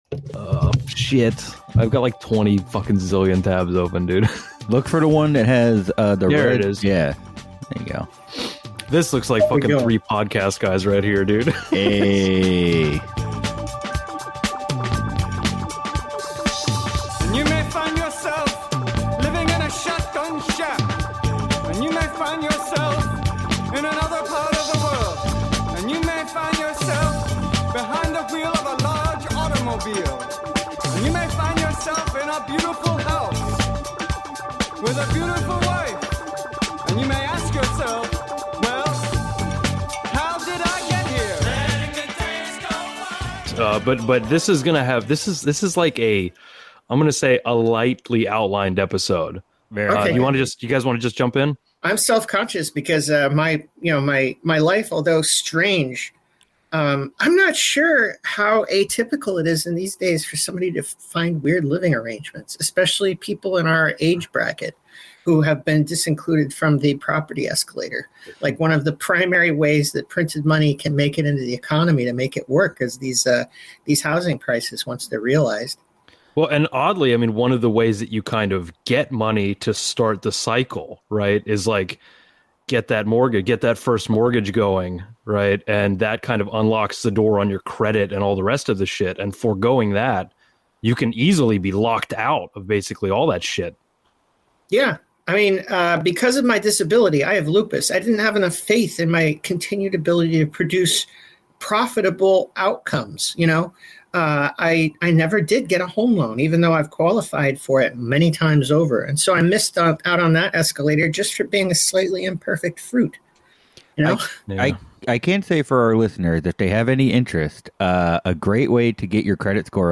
Two lifelong friends and propaganda lovers from the Gulf Coast look at and discuss media portrayals of politics from ongoing news media narratives to film and television.